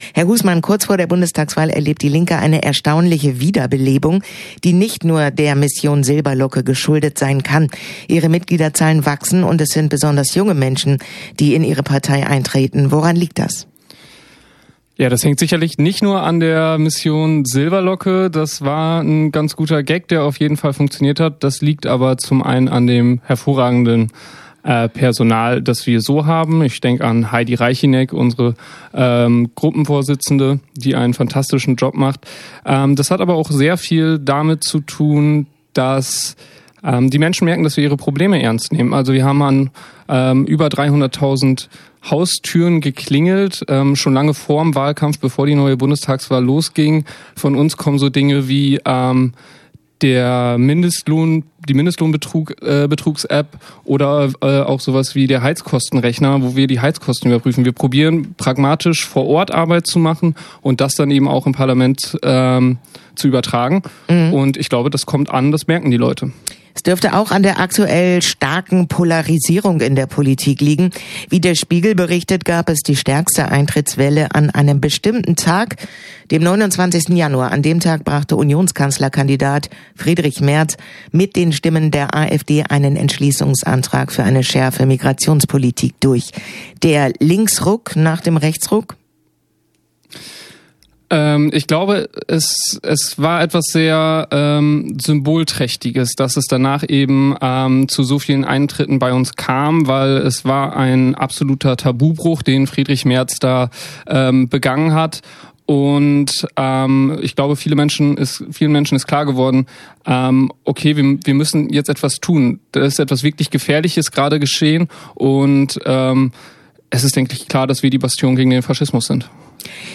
Okerwelle 104.6